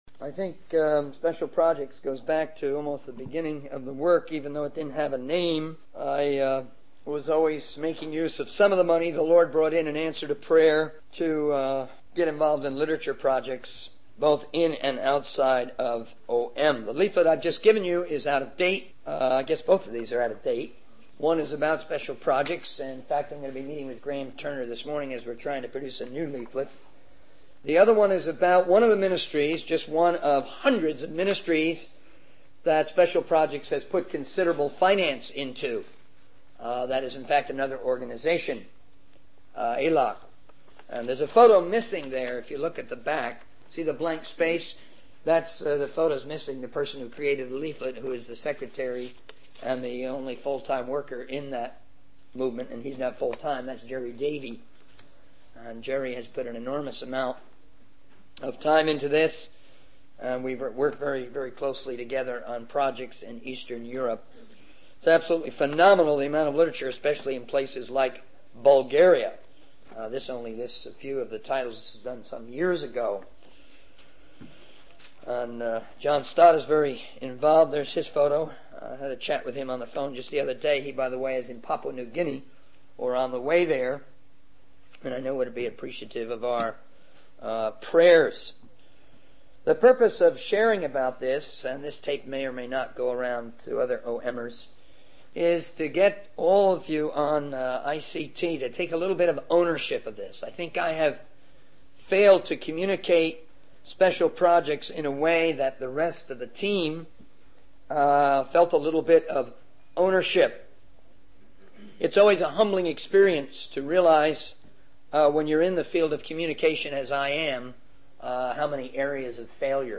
In this sermon, the speaker discusses the topic of violence in the Old Testament and how God's view of violence may differ from that of humans.